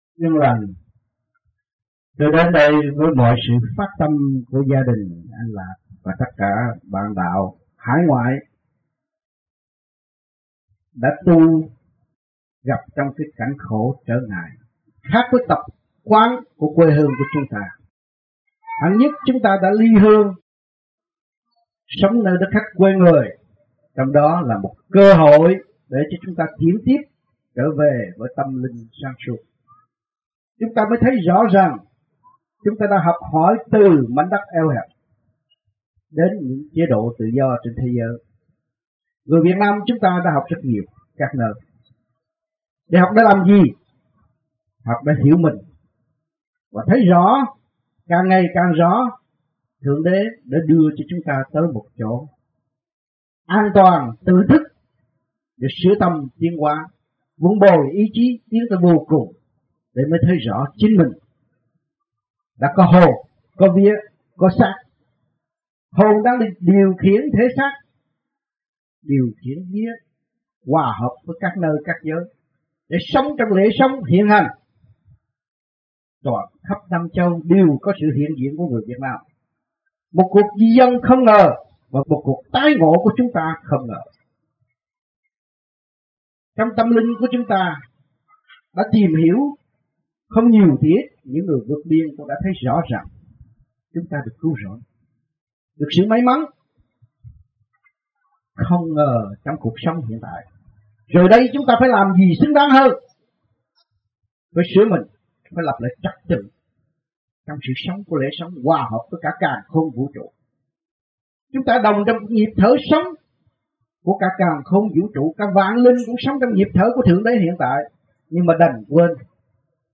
1980-07-25 - Fountain Valley - Bài Giảng